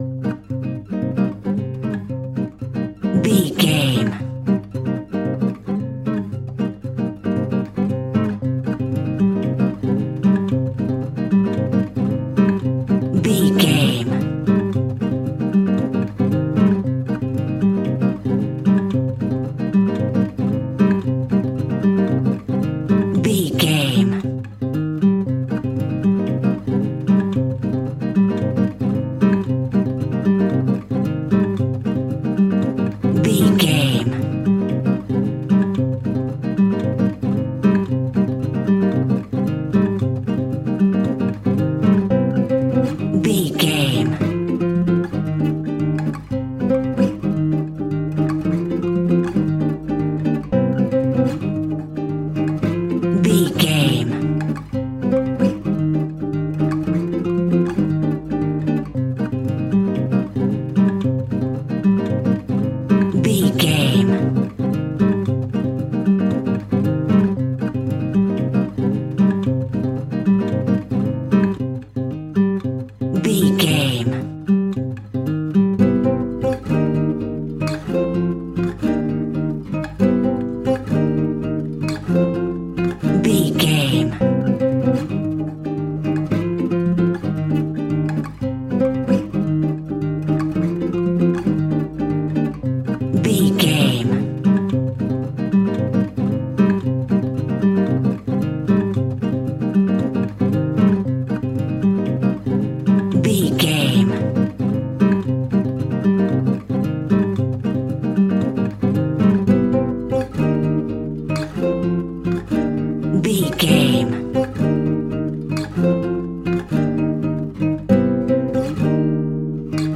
Aeolian/Minor
Fast
romantic
maracas
percussion spanish guitar